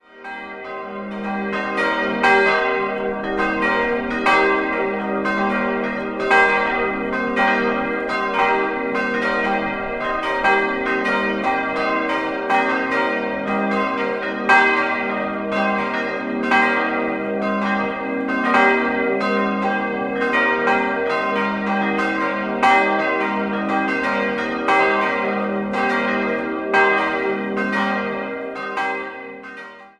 Idealquartett: g'-b'-c''-es'' Alle Glocken wurden 1957 von Georg Hofweber in Regensburg gegossen.